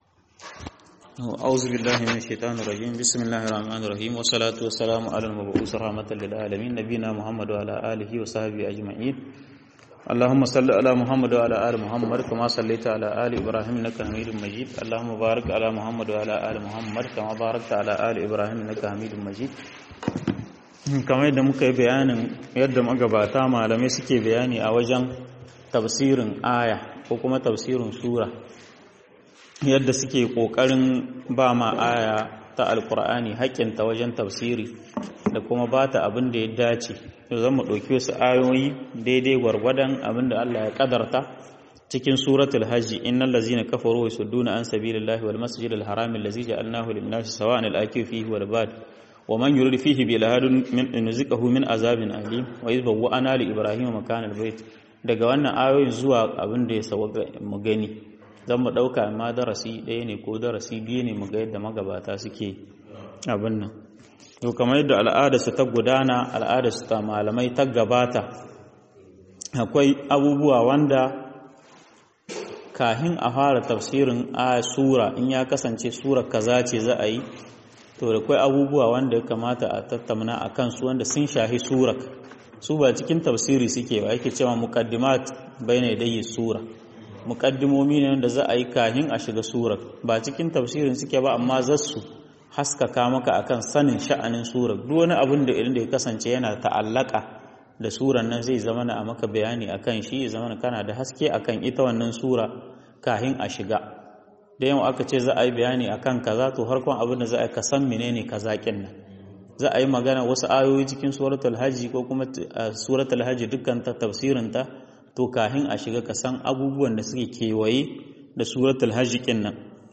هدايات آيات الحج ١ - MUHADARA